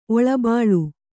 ♪ oḷabāḷu